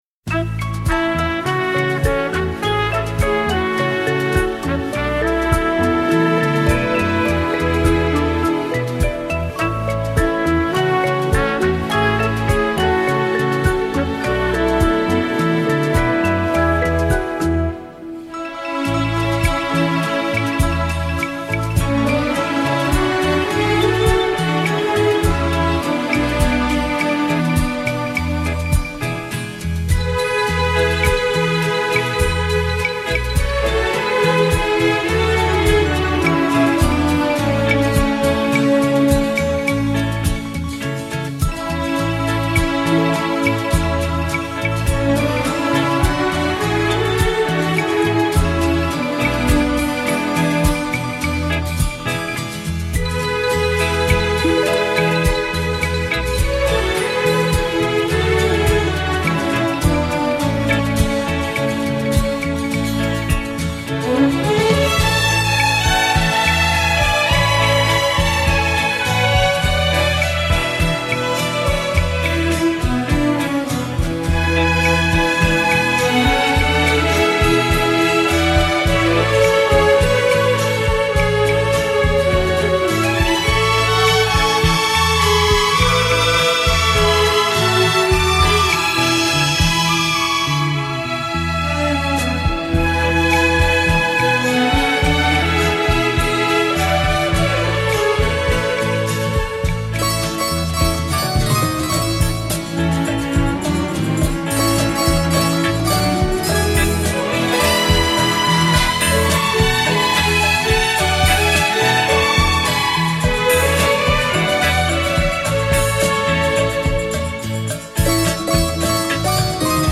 浪漫弦乐和流行风格的完美融合 精装6CD超值价答谢乐迷